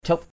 /ʧuʔ/